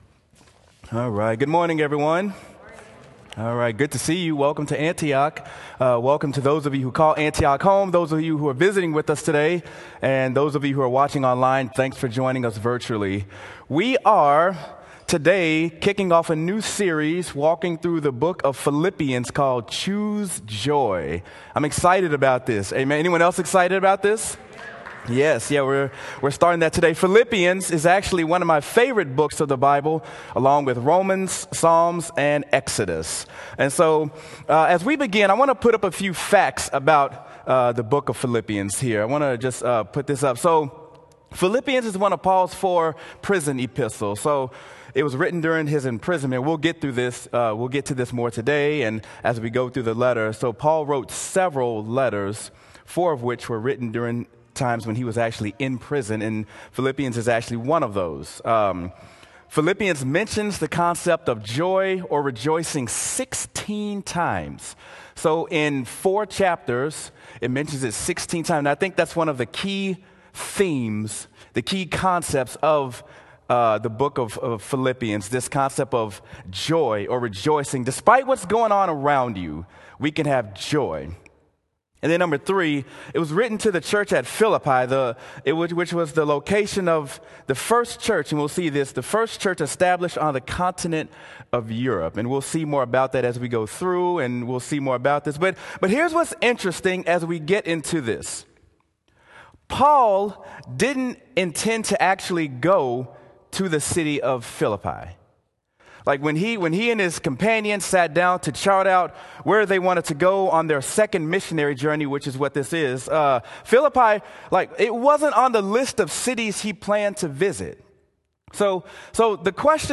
Sermon: Choose Joy: Grace and Peace to You
sermon-choose-joy-grace-and-peace-to-you.m4a